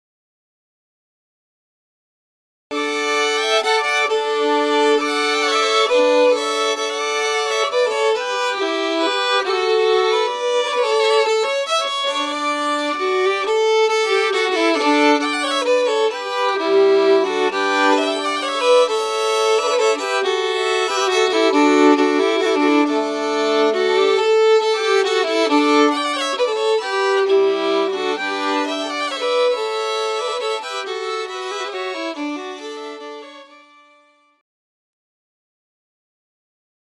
The next two tunes are examples of what we might play as pre-ceremony music:
ShortJimmywaltz.mp3